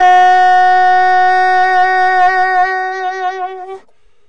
中音萨克斯（自由爵士乐） " 中音萨克斯 gb3 v115
描述：萨克斯风系列样本的第一份。
我称它为"自由爵士"，因为有些音符与其他的音符相比是失调和前卫的。这个系列包括多种衔接方式，以获得真实的表现。
标签： 中音萨克斯 爵士 采样-instruments 萨克斯 萨克斯 VST 木管乐器
声道立体声